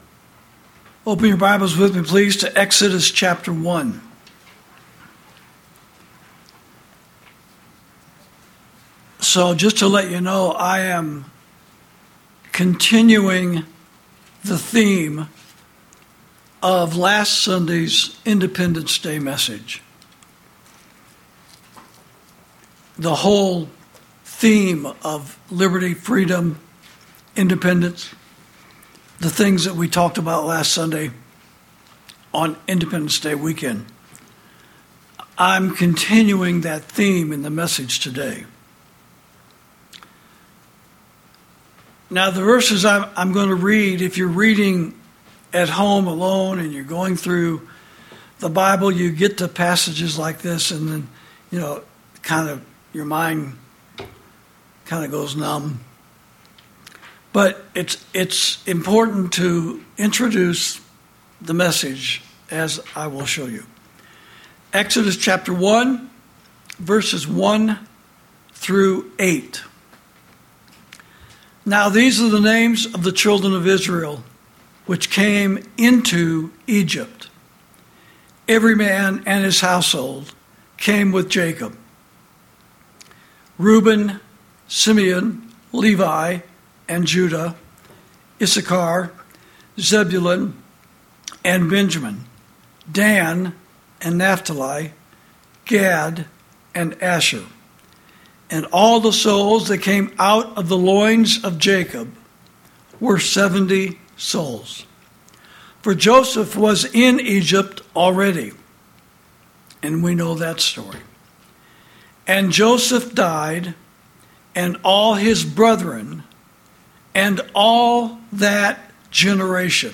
Sermons > When Bad Men Stand Up, Good Men Can't Sit Down